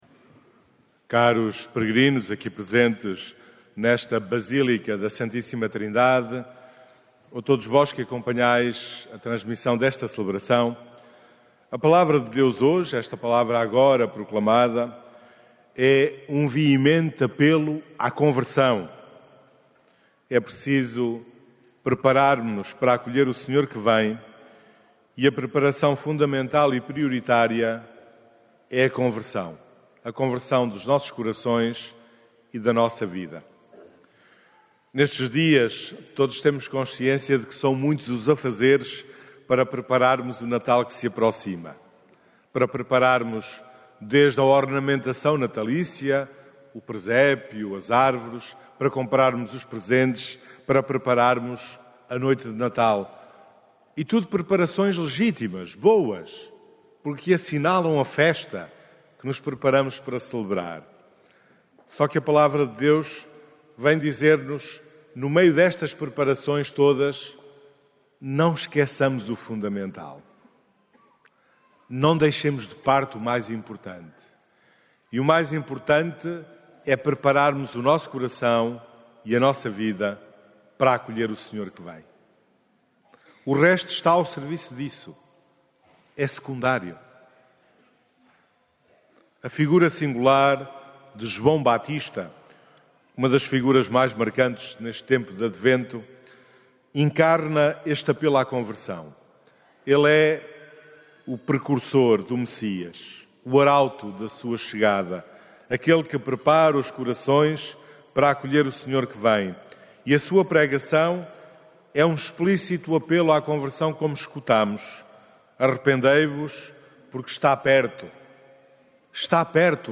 A Basílica da Santíssima Trindade acolheu, neste domingo, uma assembleia numerosa na missa das 11h00
Áudio da homilia